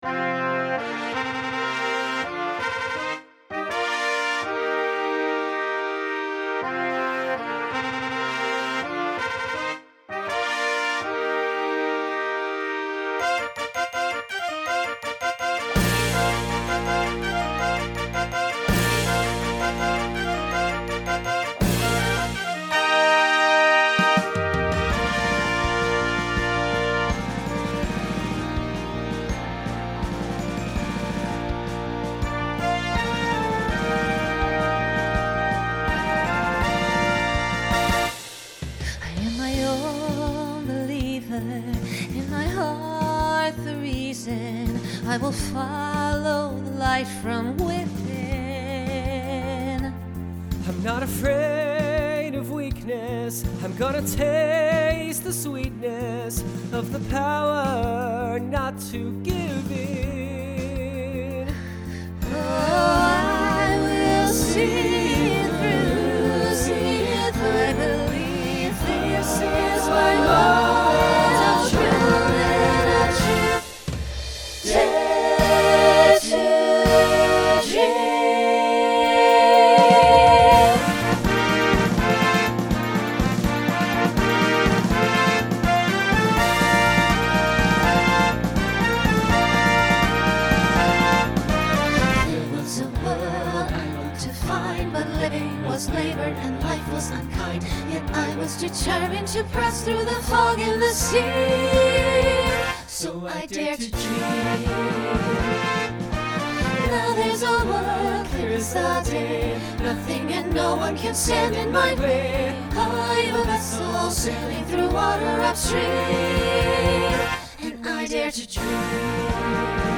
Broadway/Film , Pop/Dance
Voicing SATB